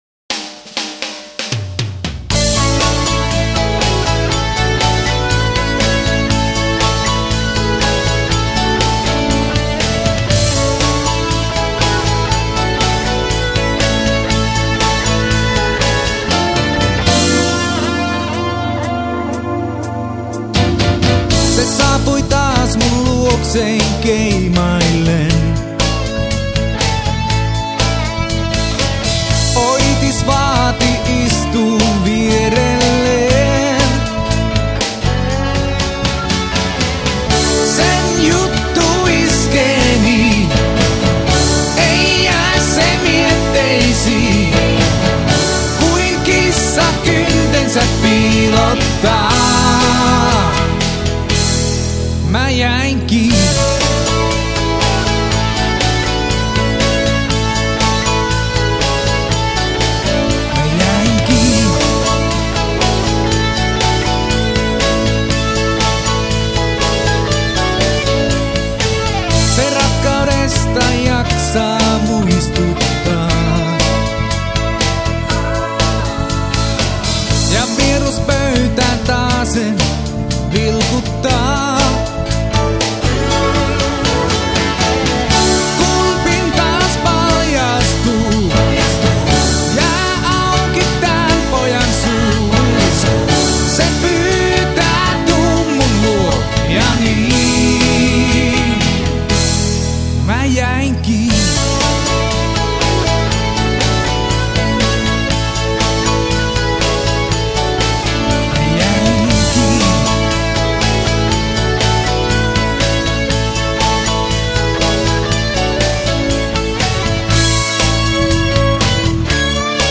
Studioversio!